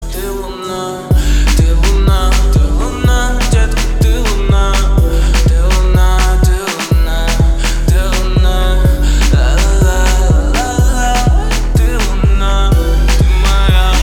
• Качество: 320, Stereo
мужской вокал
лирика
русский рэп
танцевальная музыка